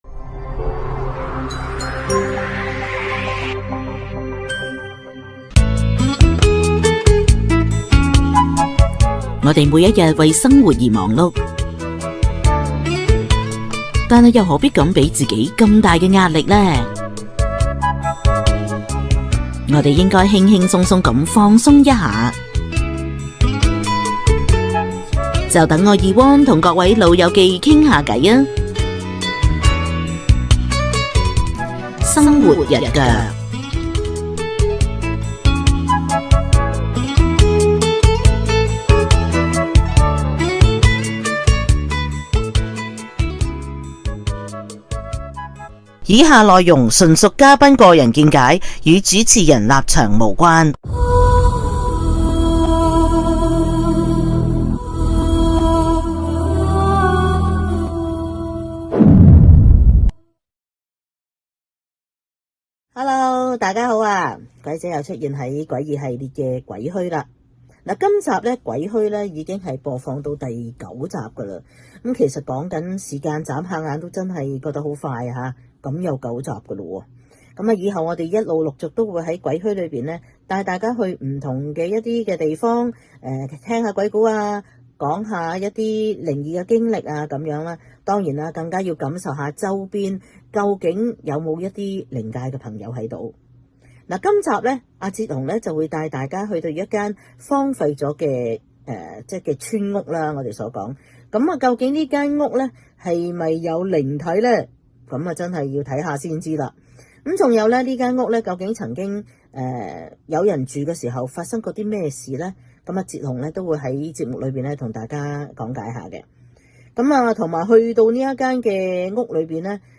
# 鬼屋講鬼故